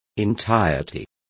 Complete with pronunciation of the translation of entirety.